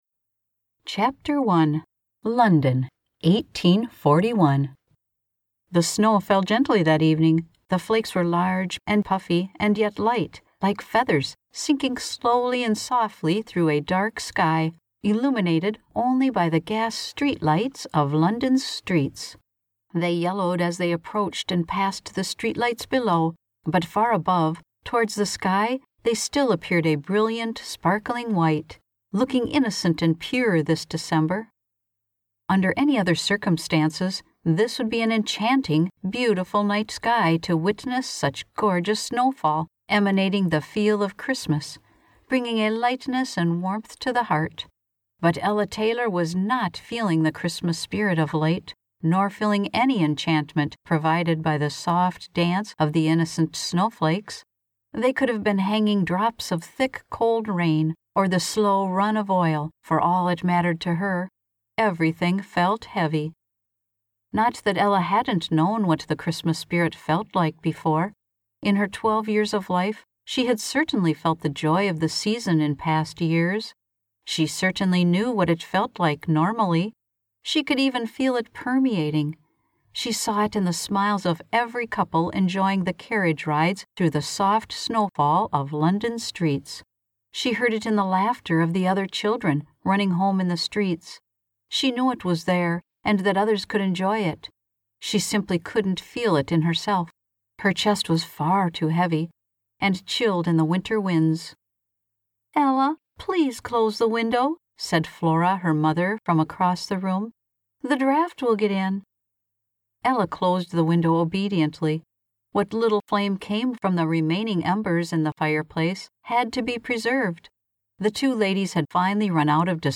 Audiobook Narrator and Voiceover Artist
A warmhearted, authentic and vibrant voice.
My happy place is tucked away in my studio narrating my latest book.